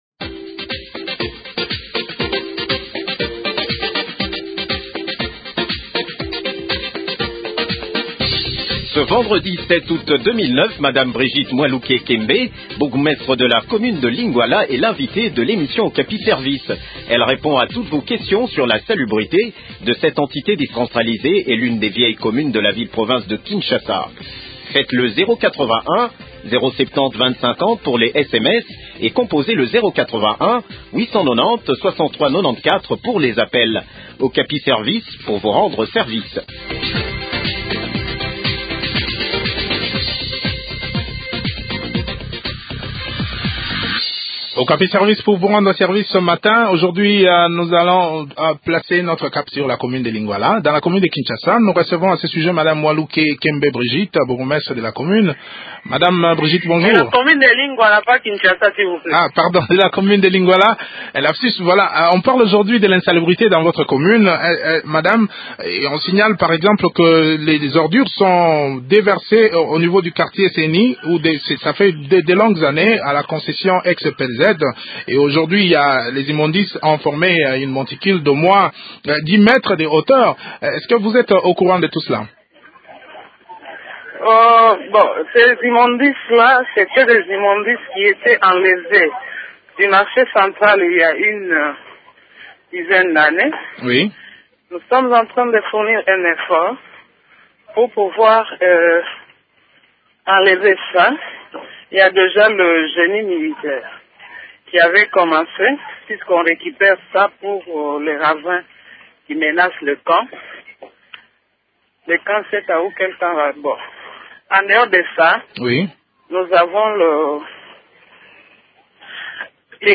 madame Brigitte Mwaluke Kembe, bourgmestre de Lingwala.